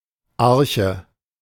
Arche (German: [ˈaʁçə]